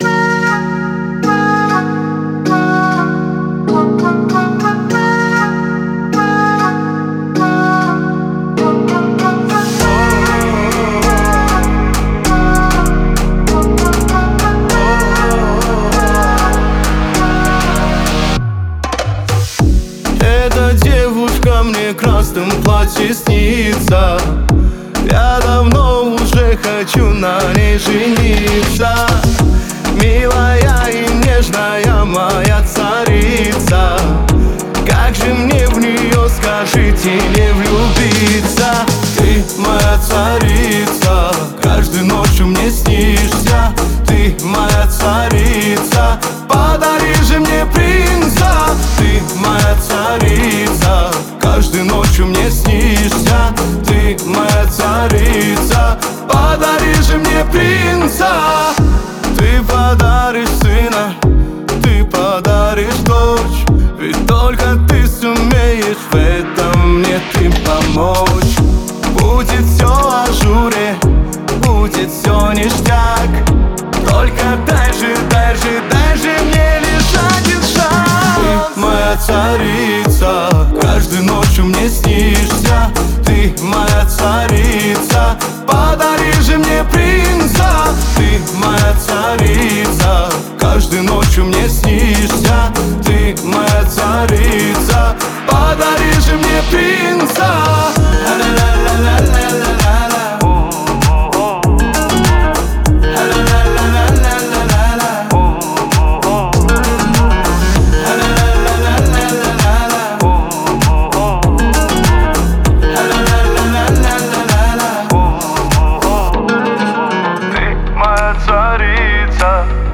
это яркая композиция в жанре поп с элементами этники